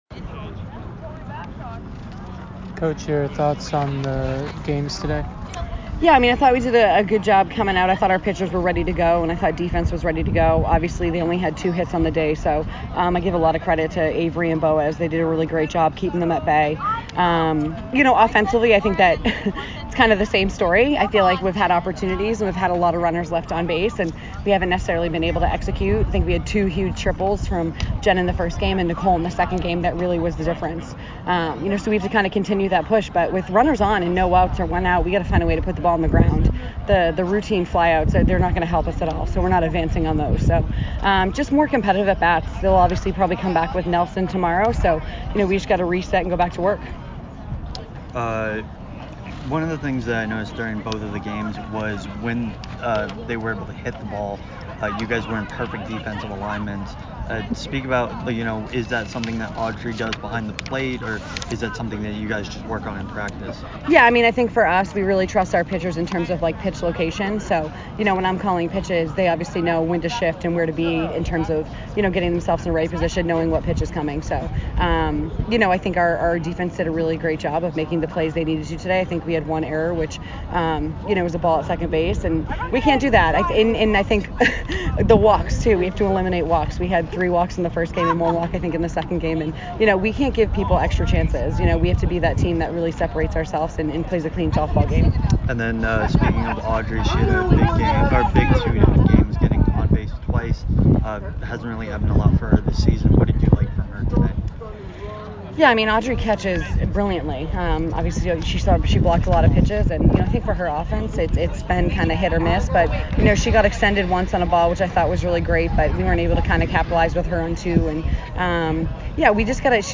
Holy Cross Softball Postgame Interview